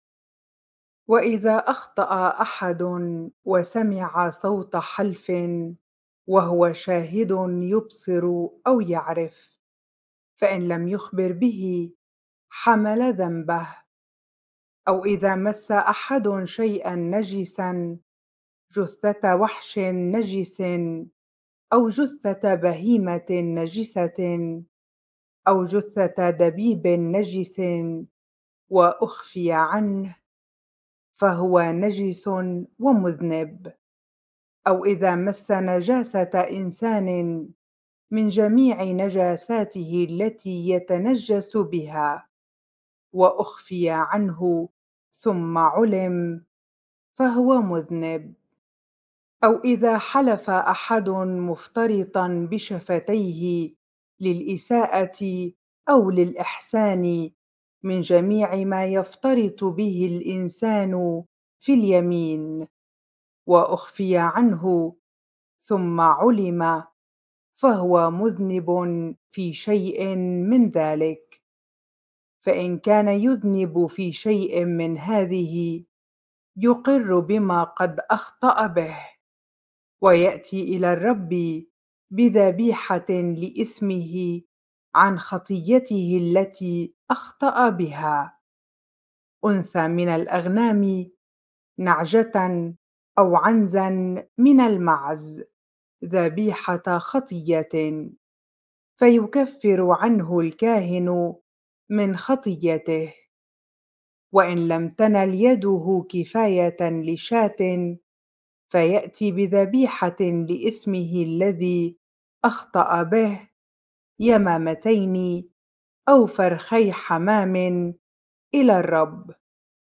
bible-reading-leviticus 5 ar